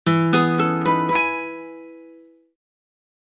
sms 3